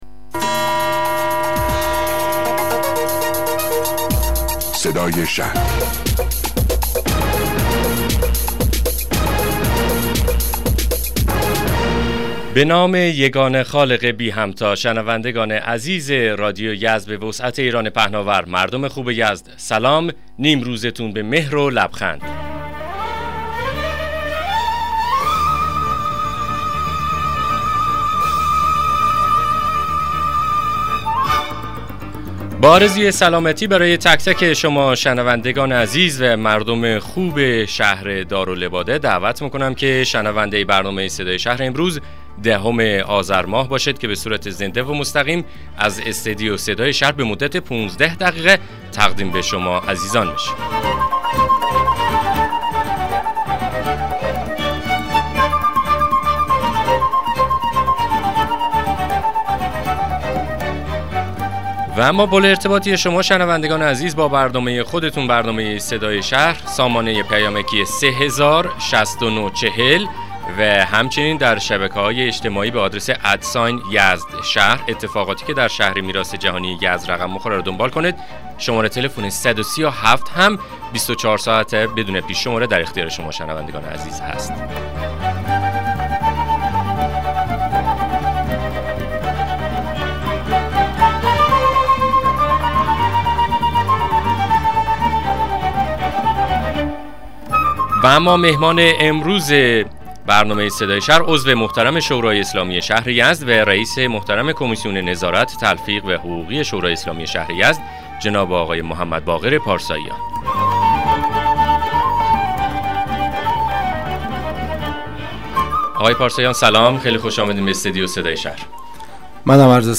مصاحبه رادیویی برنامه صدای شهر با حضور محمدباقر پارساییان رییس کمیسیون نظارت و حقوقی شورای اسلامی شهر یزد